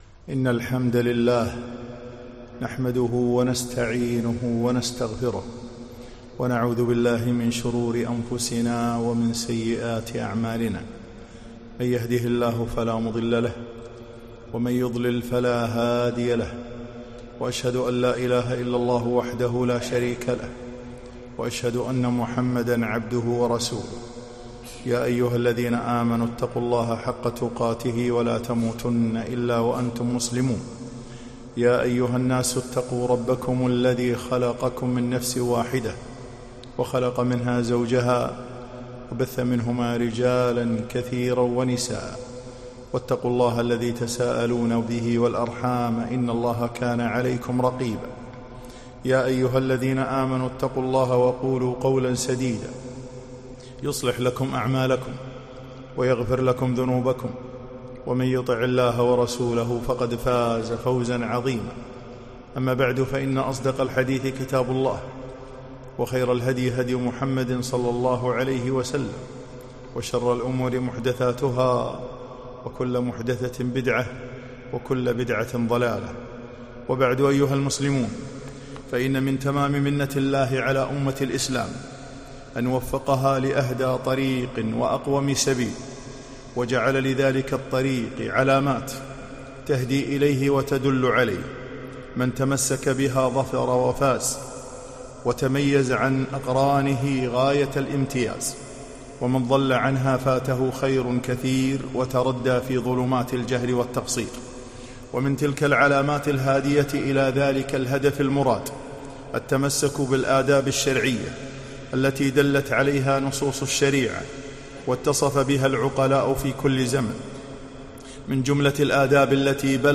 خطبة - الأدب رأس الفضائل